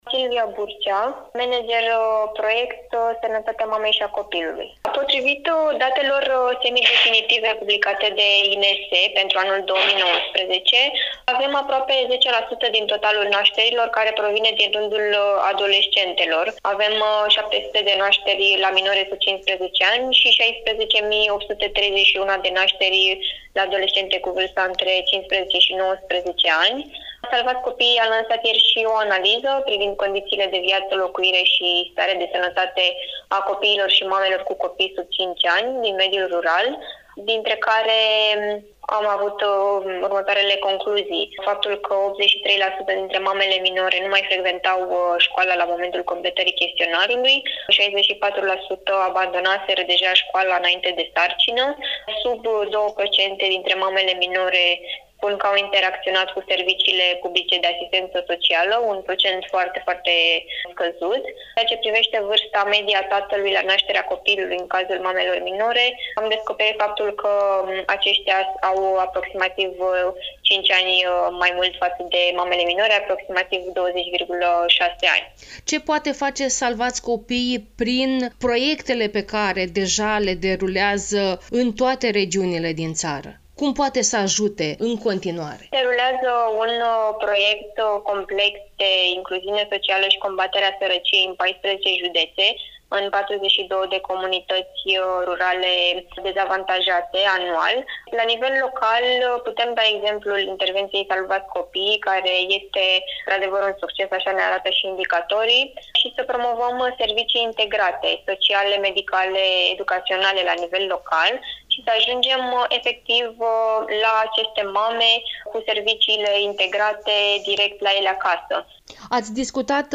(INTERVIU) Aproape un sfert (23%) dintre mamele sub 18 ani din UE trăiesc în Romania